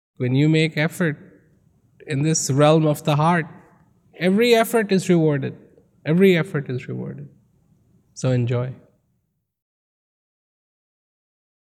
Transcripts of Rawat's speeches cannot do justice to Rawat's astonishing delivery, they have to be heard to be appreciated. Mp3 copies of these excerpts are recorded at high quality (256Kbps) to ensure no whispered nuance or frenzied climax is missed.